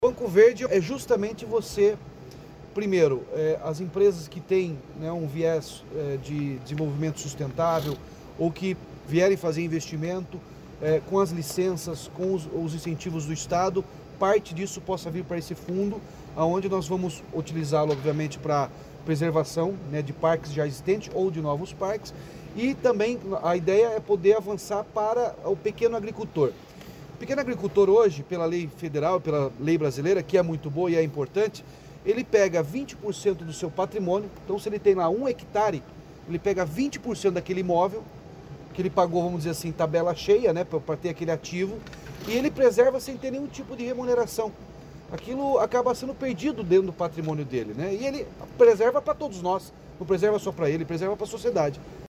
Sonora do governador Ratinho Junior sobre o Banco Verde